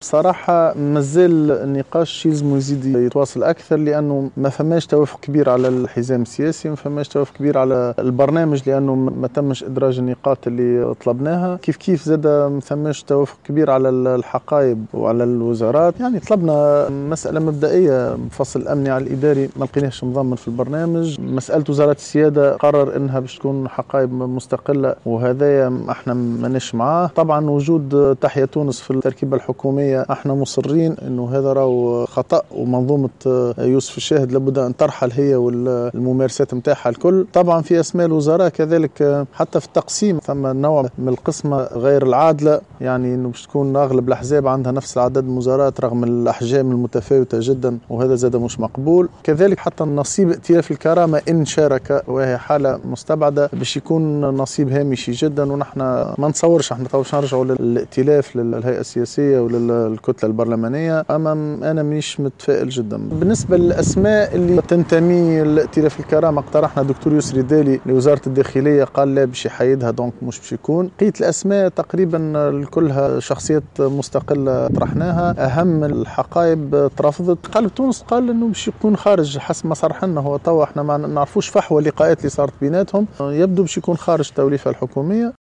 وأشار مخلوف في تصريح إعلامي إثر لقائه رئيس الحكومة المكلّف عشيّة اليوم الجمعة إلى أنّ التقسيم المقترح للوزارات بين الأحزاب من قبل الياس الفخفاخ "غير عادل " موضّحا أنّه سيكون للأحزاب المشاركة في المشاورات نفس النصيب من الحقائب رغم تفاوت ثقلها السياسي والبرلماني واصفا الأمر بأنه غير مقبول ومعربا عن "عدم تفاؤله في هذا الجانب".